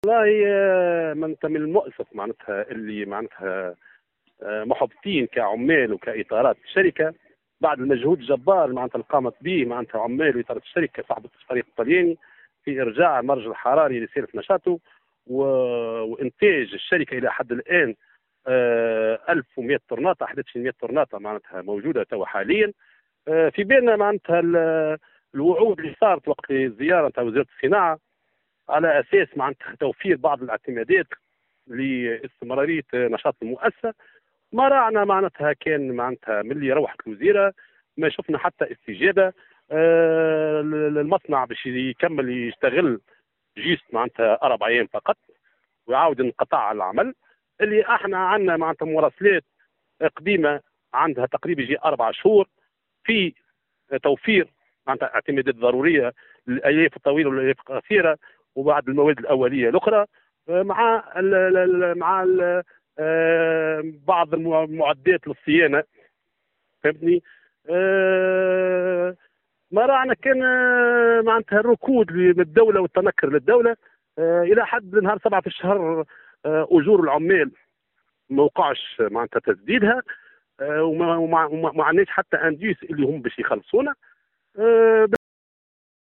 عمال وإطارات شركة عجين الحلفاء و الورق بالقصرين يحتجون ويغلقون الطريق(تصريح)
مزيد التفاصيل في التصريح التالي :